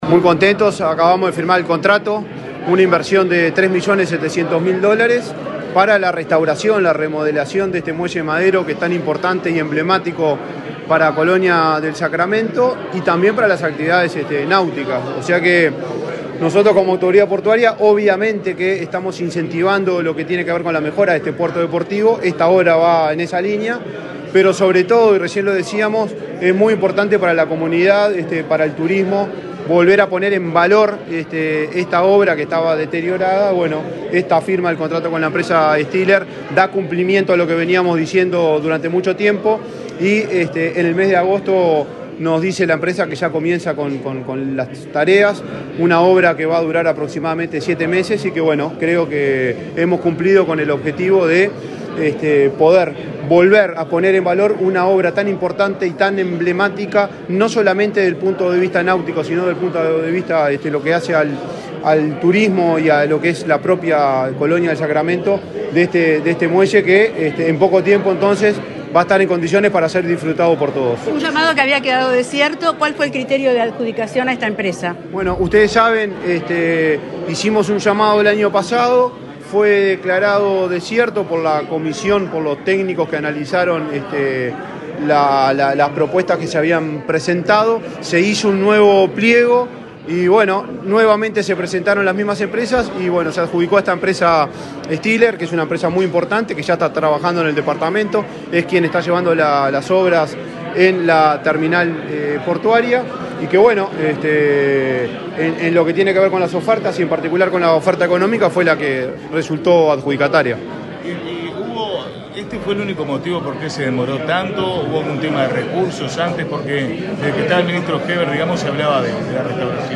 Declaraciones del presidente de la ANP, Juan Curbelo
El presidente de la Administración Nacional de Puertos (ANP), Juan Curbelo, dialogó con la prensa en Colonia, durante el acto de firma de un convenio